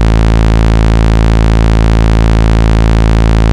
as opposed to my ATC-1's sawtooth... (which is what I'm used to)
(all of these are with the filter wide open) I got curious when I was listening to the raw oscs and it wasn't what I was expecting.
atcOSC.wav